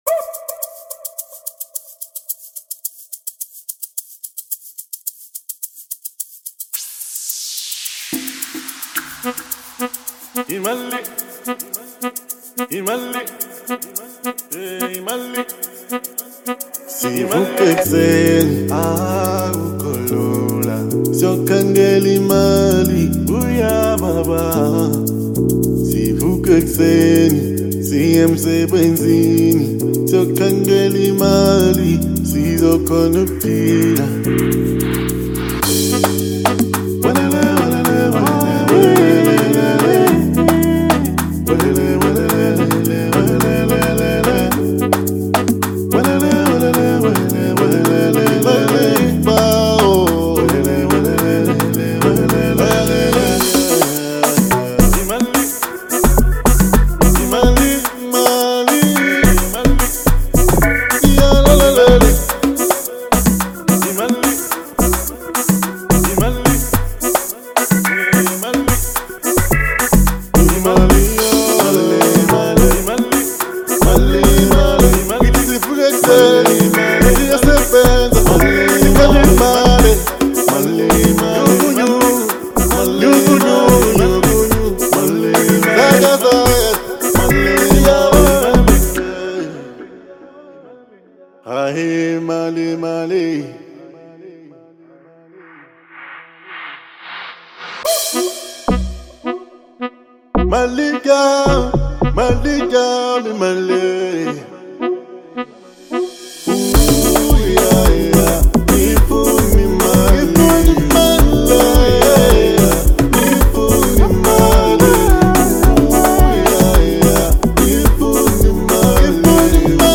South African Music.